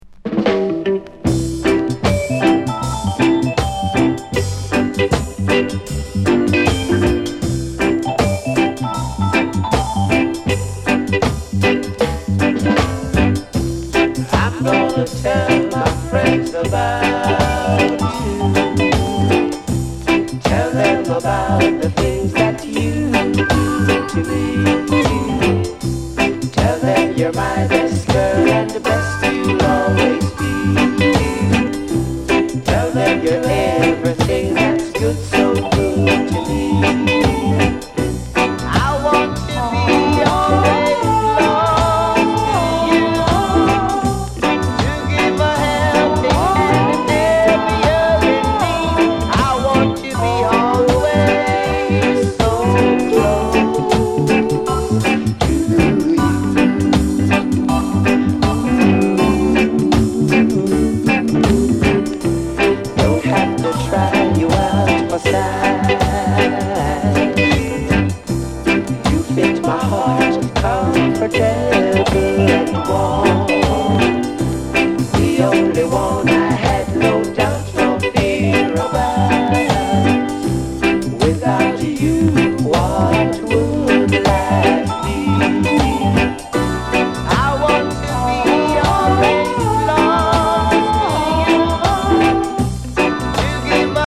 LP]ロックステディーレゲエ